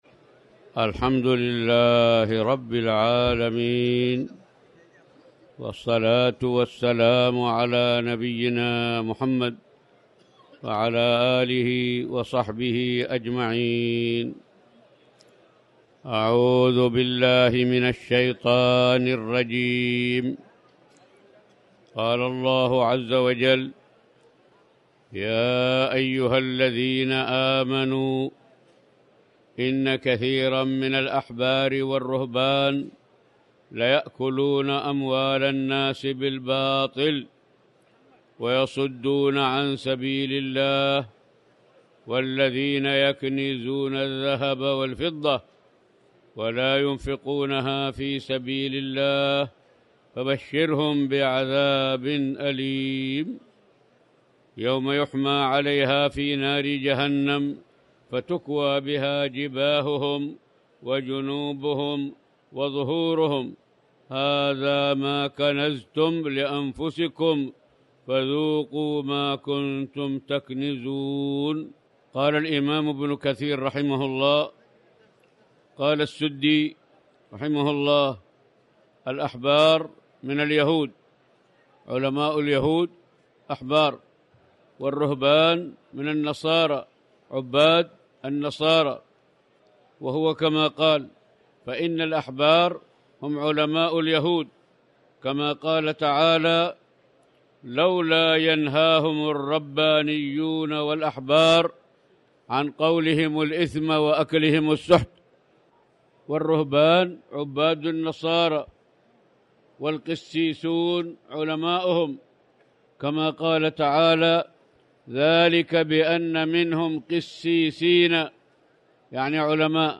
تاريخ النشر ٢٤ رمضان ١٤٣٩ هـ المكان: المسجد الحرام الشيخ